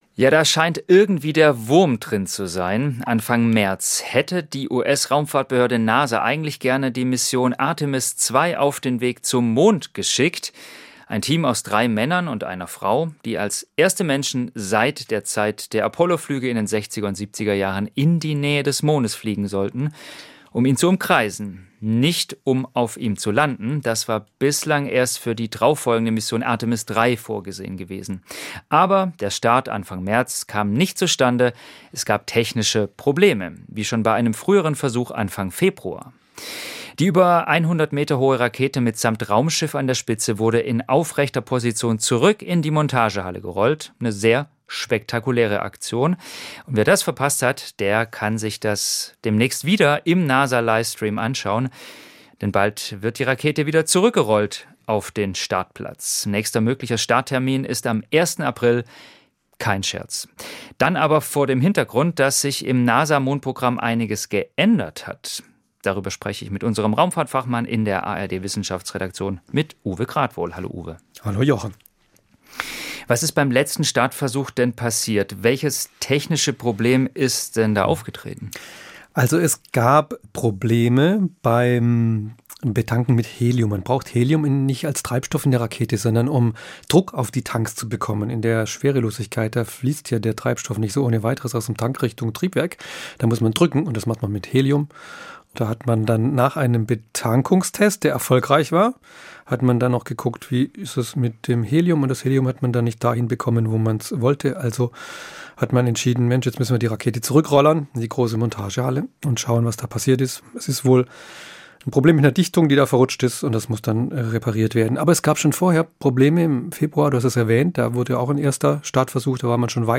im Gespräch mit